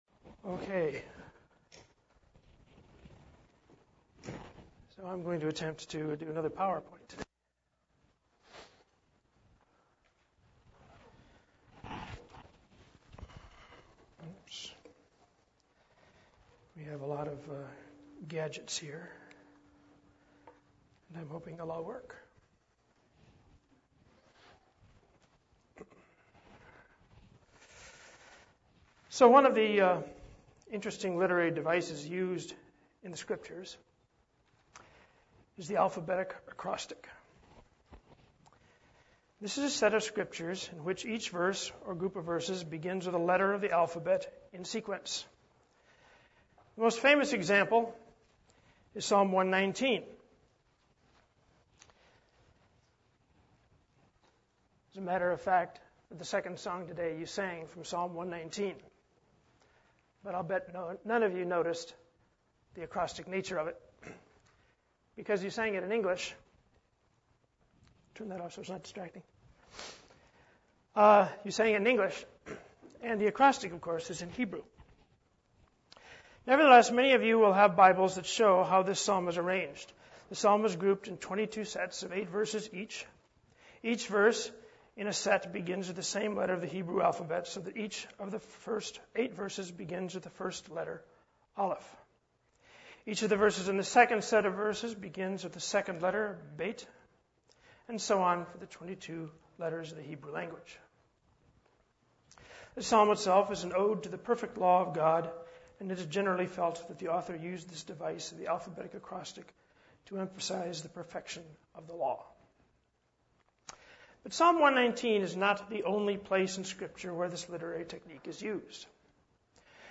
Given in San Diego, CA
UCG Sermon Studying the bible?